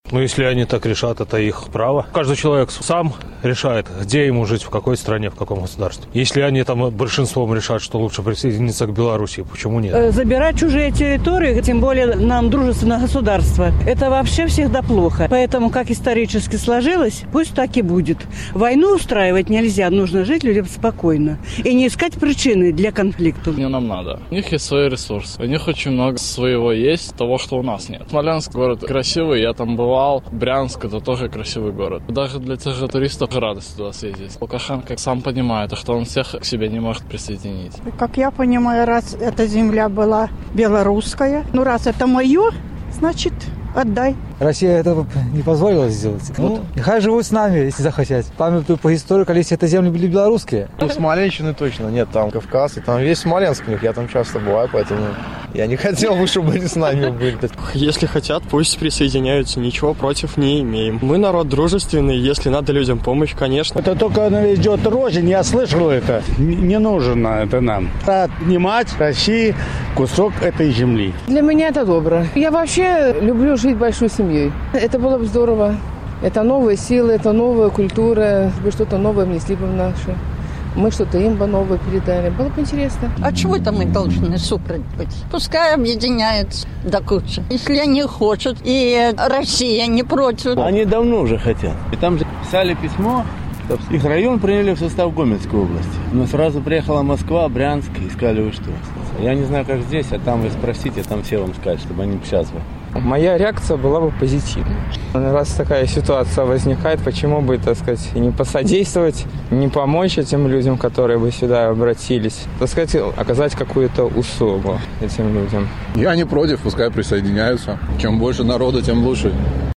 Адказваюць жыхары Магілёва.